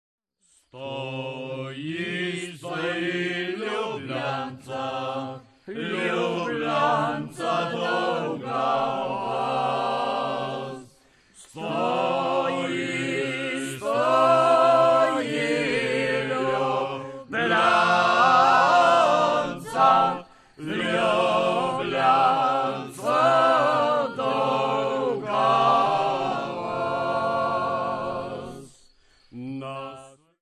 chanson populaire originaire de Basse-Carniole
mais n'est guère entraînant